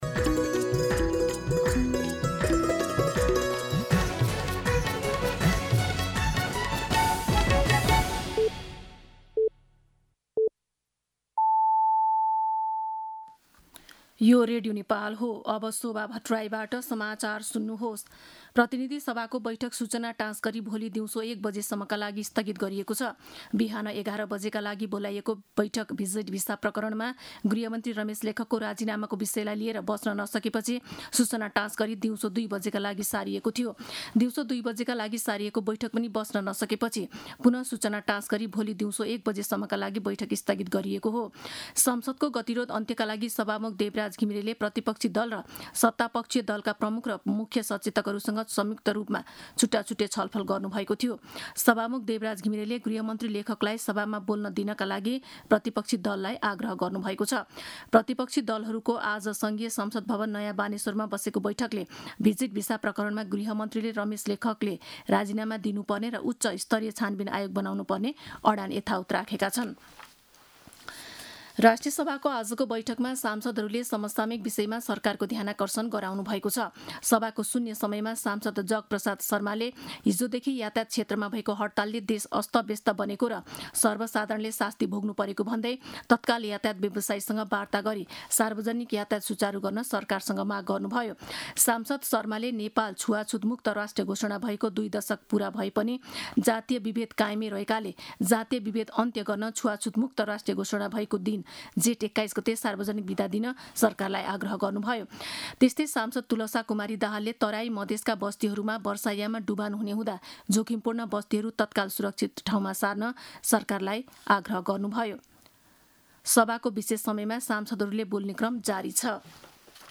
दिउँसो ४ बजेको नेपाली समाचार : २० जेठ , २०८२